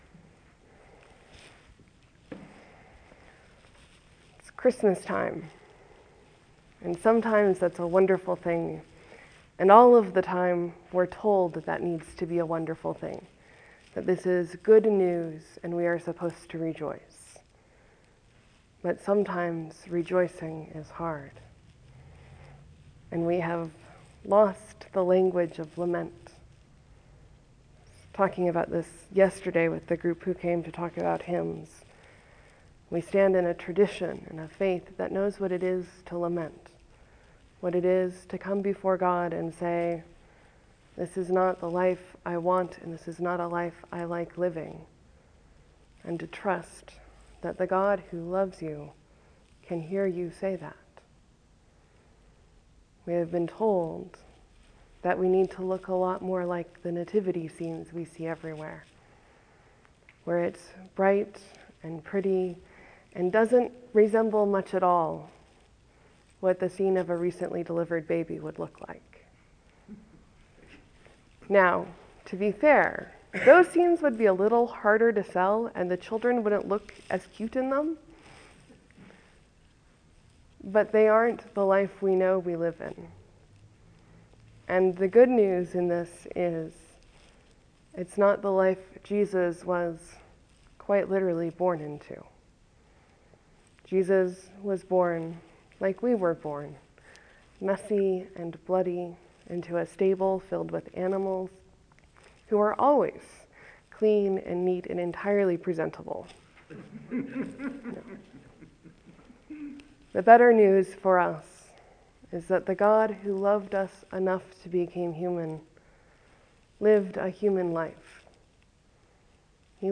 Blue Christmas or Longest Night services are designed to be a place for those who find the winter holidays a time of grief, stress, sadness, depression, or struggle. At St Peter’s this year it was a service with lots of prayer and quiet.